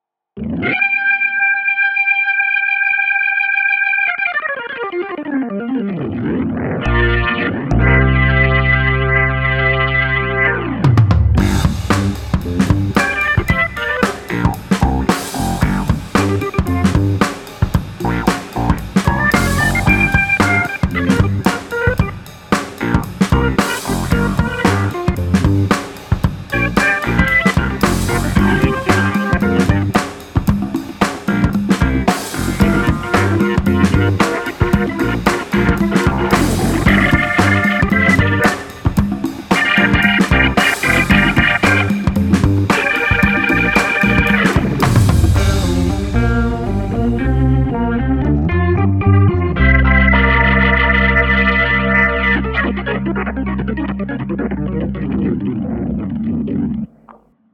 五个传奇的拉杆风琴
真实重现五个经典风琴之声
古典，独特的拉杆管风琴之声。
比HAMMOND®管风琴的声音更干净、更稳定。
声音类型： 晶体管和音轮管风琴